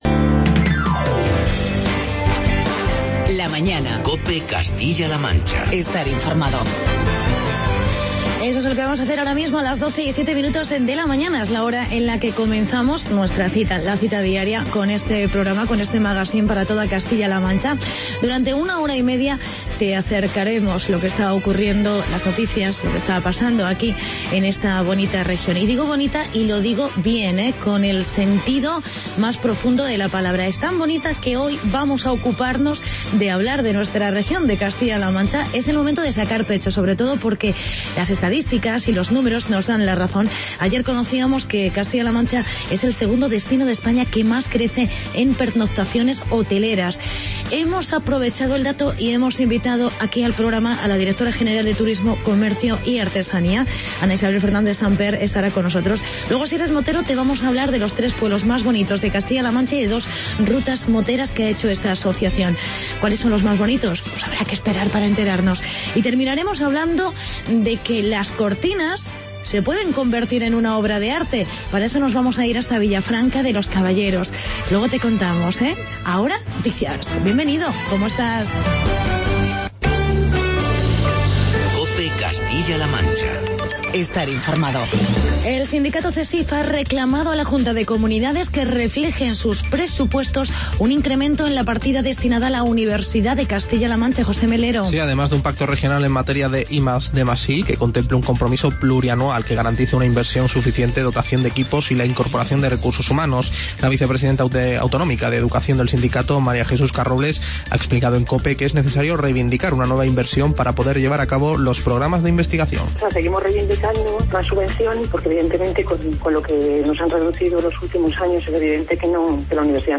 Entrevistamos a la Directora General de Turismo, Ana Isabel Fernández Samper.